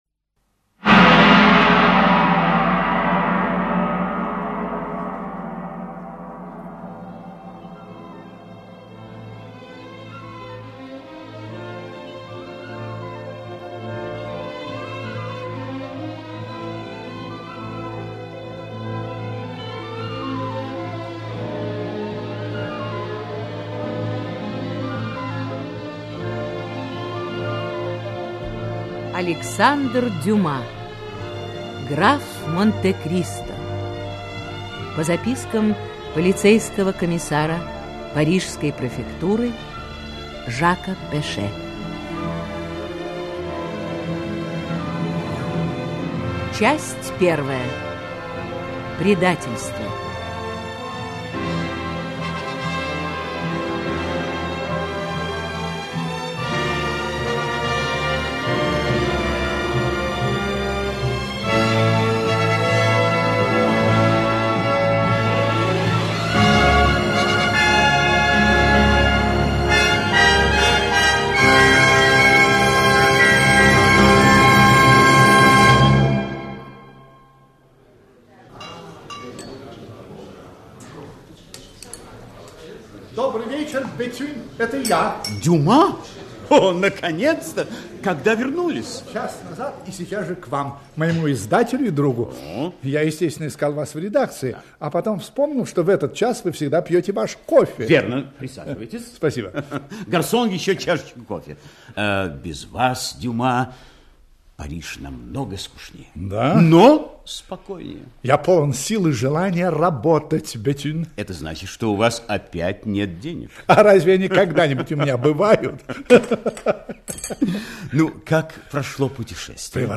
Аудиокнига Граф Монте-Кристо (спектакль) | Библиотека аудиокниг
Aудиокнига Граф Монте-Кристо (спектакль) Автор Александр Дюма Читает аудиокнигу Актерский коллектив.